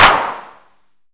048 - Clap-1.wav